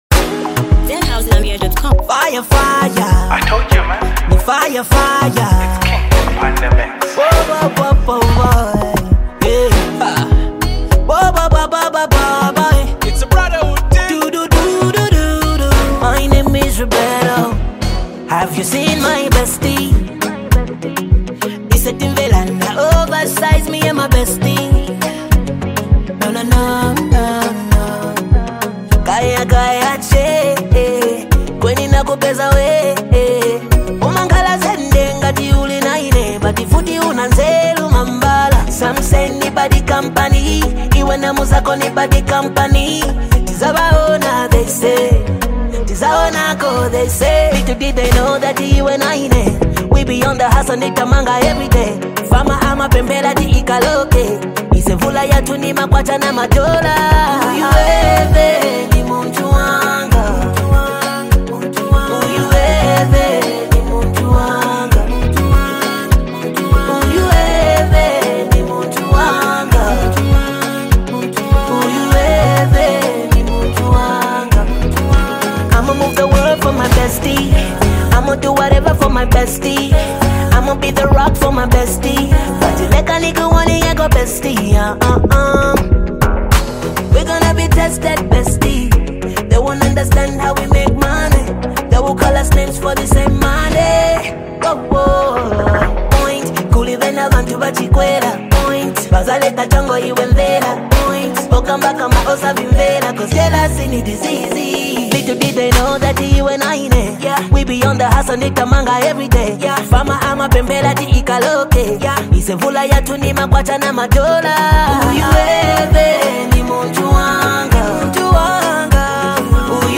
” is a smooth blend of love, loyalty, and deep connection.